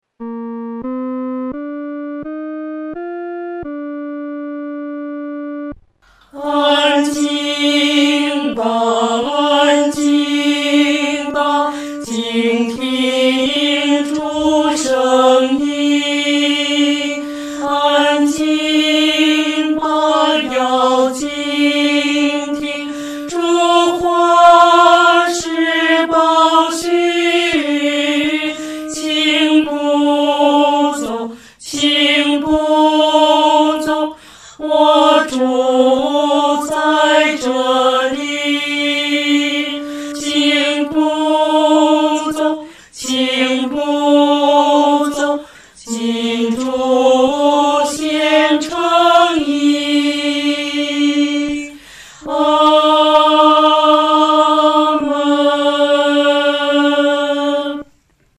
合唱
四声 下载
特别是副歌伴奏部分的跳音，使人仿佛听到脚步的移动，但整个旋律却是异常宁静。